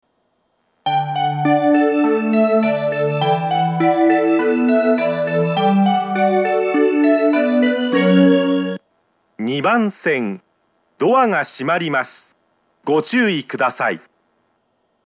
交換があると信号開通が極端に遅くなるため、メロディーは少ししか鳴りません。
２０１０年３月以前に放送装置を更新し、発車メロディーに低音ノイズが被るようになりました。
無人化の少し前に放送装置が更新され、巌根型の放送になっています。
熱海・東京方面   ２番線接近放送
２番線発車メロディー